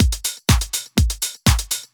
House1.wav